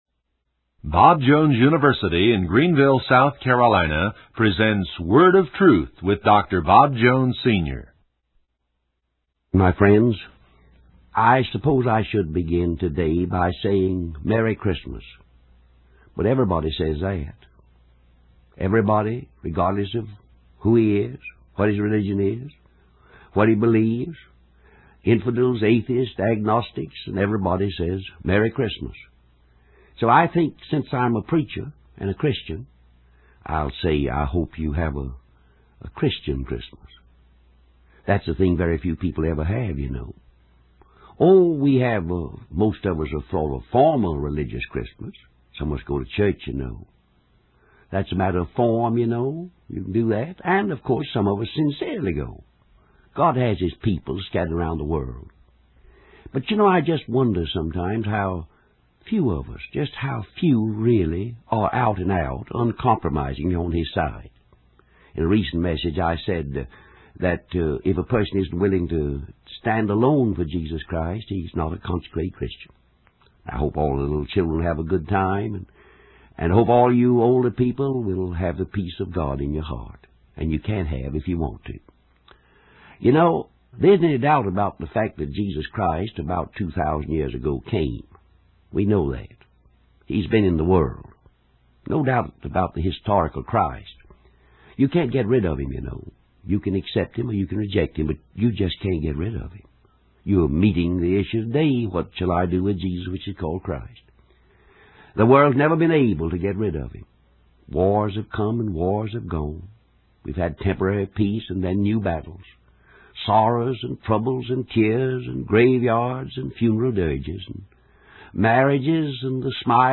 In this sermon, Dr. Bob Jones Sr. shares a story about a little orphan boy in a country school who willingly took the punishment of 10 lashes without his coat for another student. Dr. Jones uses this story to illustrate the concept of vicarious substitution, explaining that Jesus Christ took our place and suffered the lashes of God's judgment on our behalf.